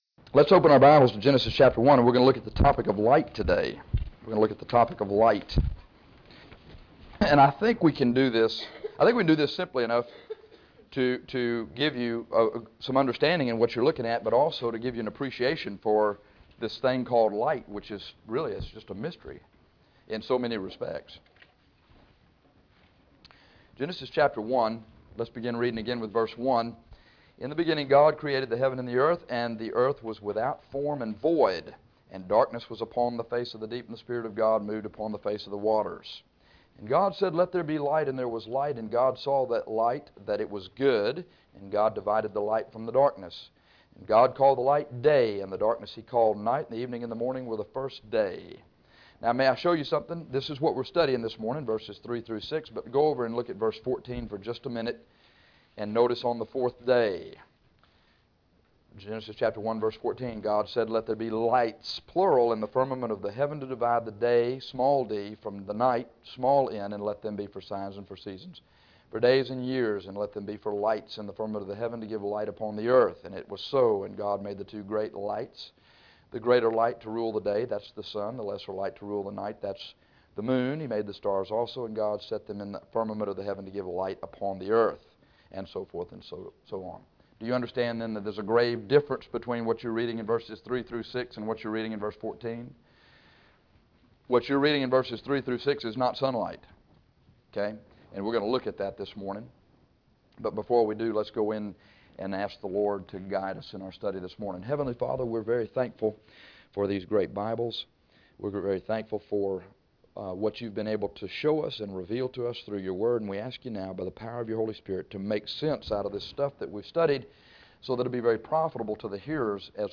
This lesson studies light.